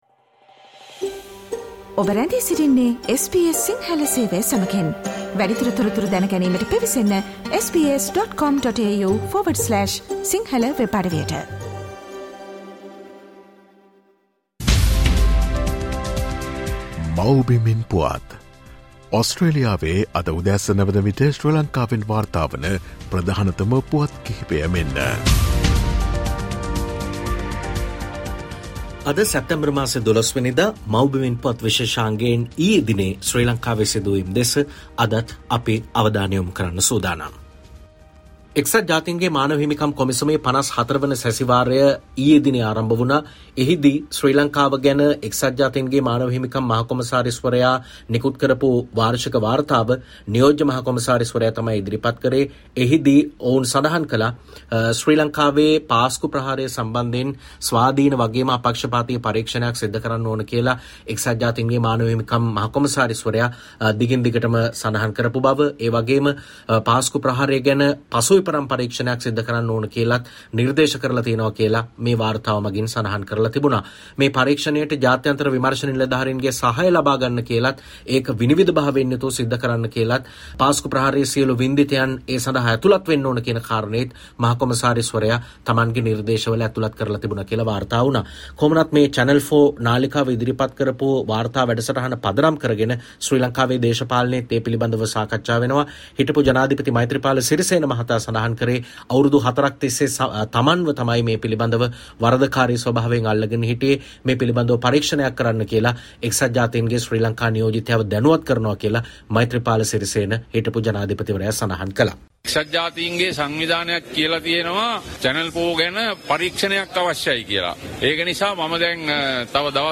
Here are the most prominent News Highlights of Sri Lanka on 8 September 2023.